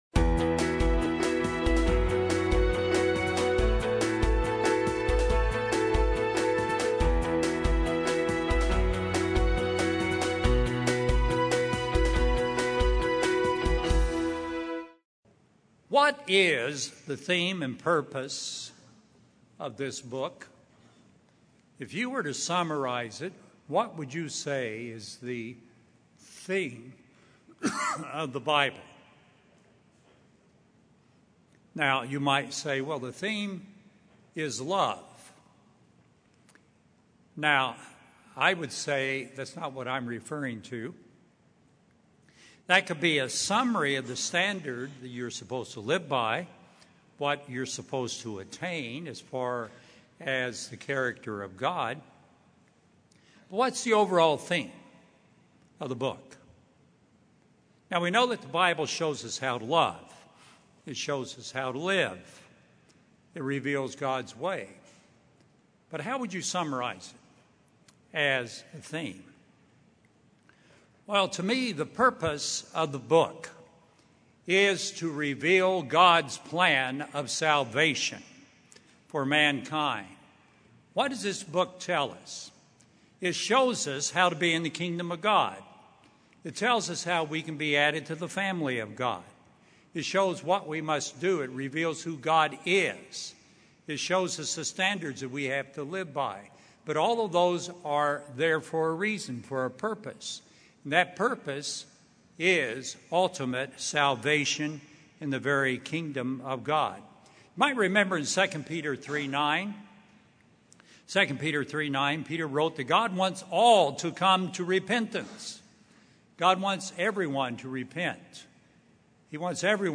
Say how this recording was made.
In this sermon, we hear about the wonderful opportunity we have to be a part of God's family and how He gives us salvation through His power. This sermon was given on the Feast of Pentecost.